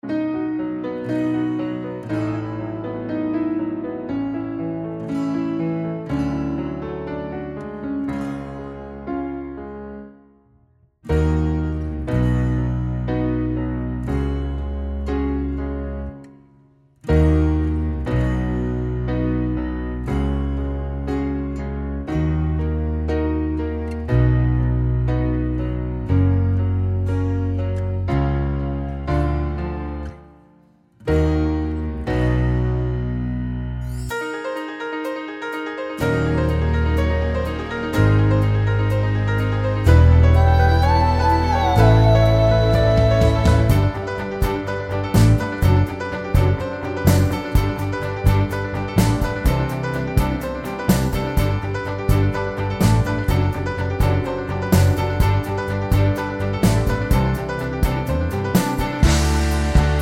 no Backing Vocals Soundtracks 4:17 Buy £1.50